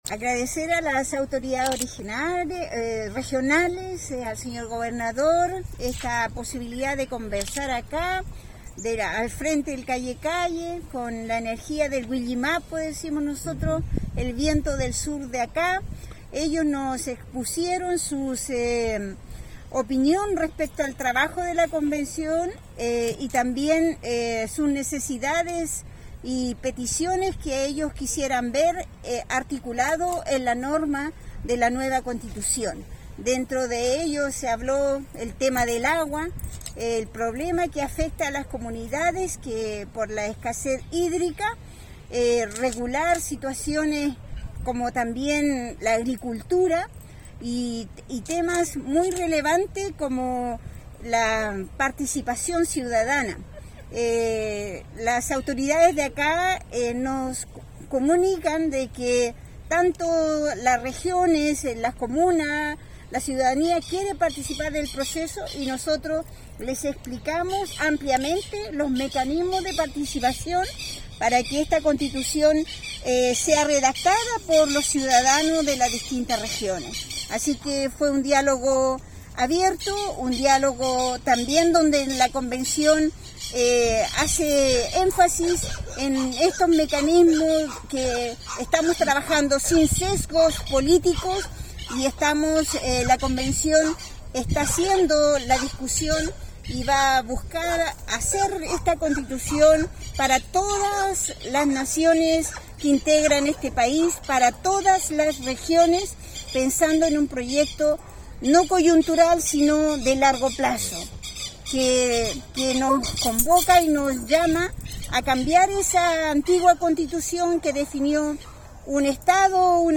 Cuña_Elisa-Loncón_encuentro-Convención.mp3